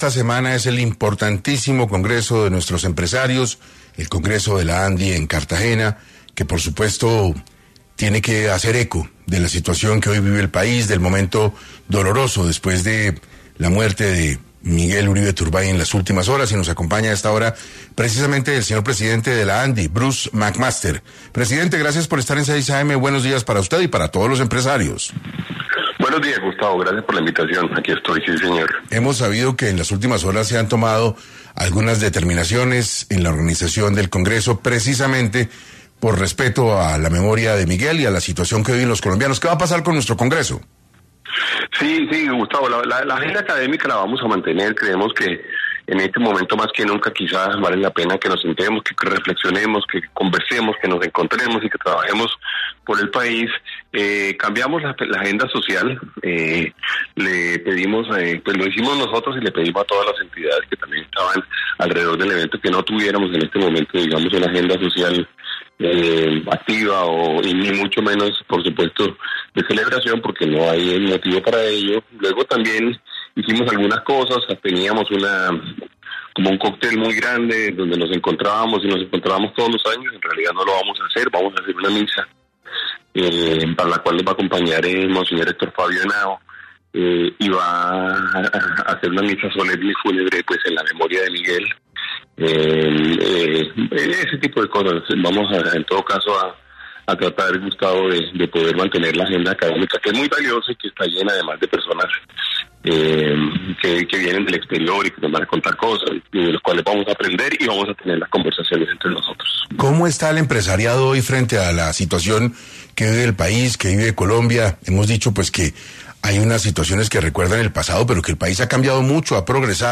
En Caracol Radio estuvo Bruce Mac Master, presidente de la Andi